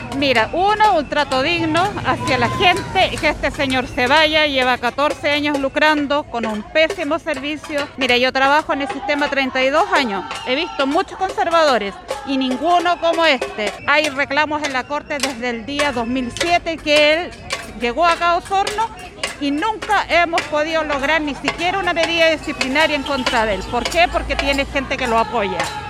Una de las presentes en la manifestación expuso su postura frente al susodicho.